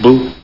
Amiga 8-bit Sampled Voice
oooff.mp3